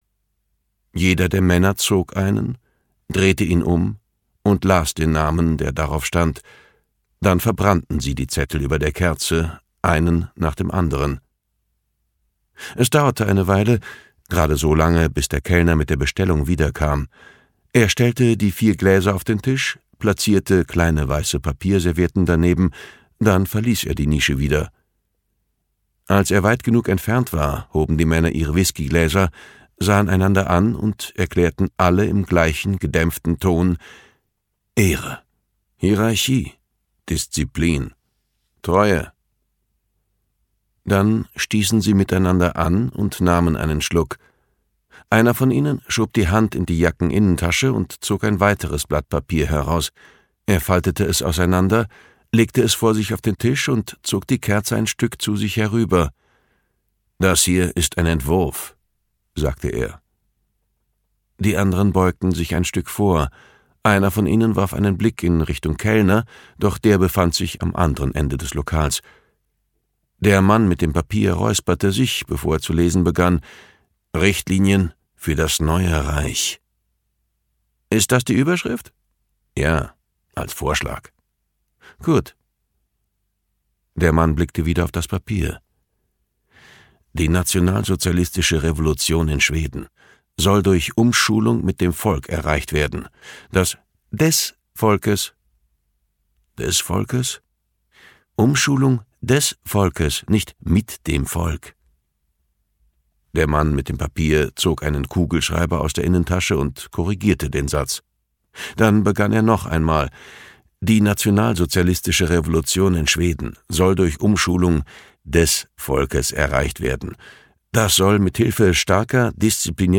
Die Strömung (DE) audiokniha
Ukázka z knihy